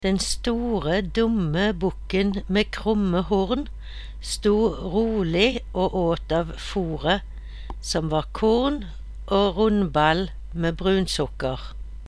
Ny side 1 Lytt til dialekteksemplene nedenfor.